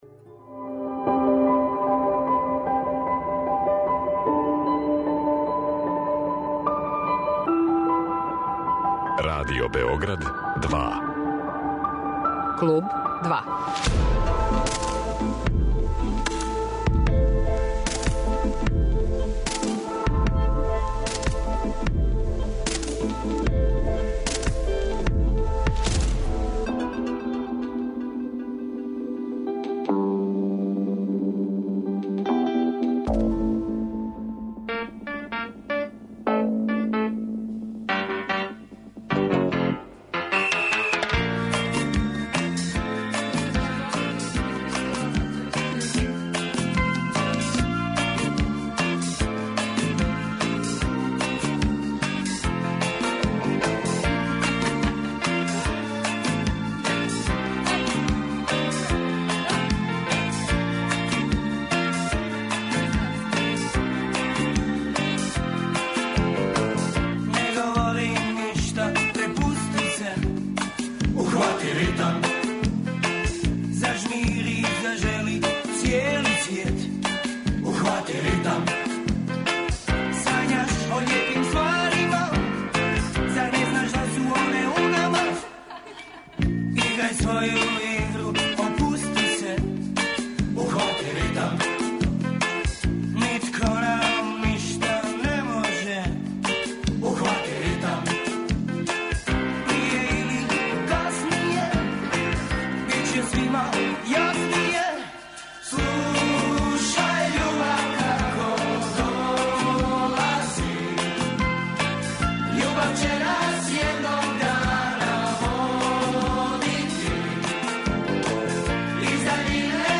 Гост емисије Клуб 2 је Аки Рахимовски, члан групе Парни ваљак.